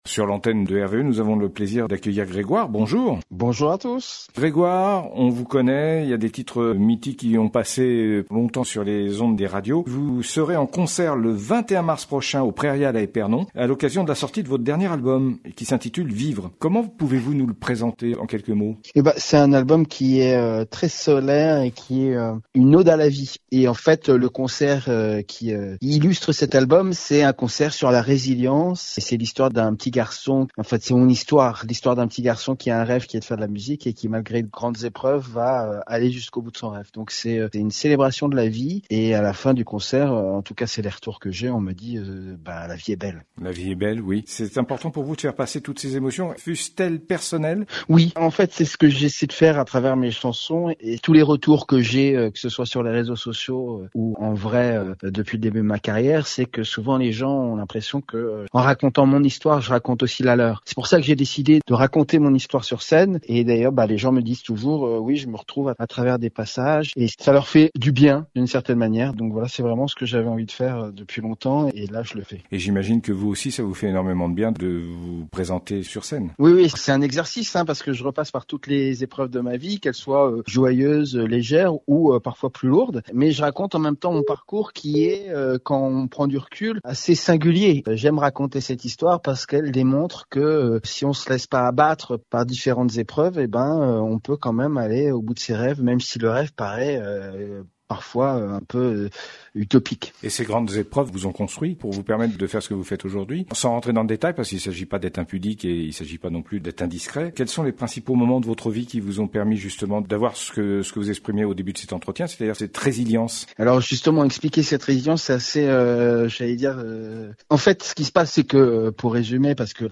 Interview de Grégoire pour son concert du 21 mars 2026 à 20h30 aux Prairiales à Epernon - Radio RVE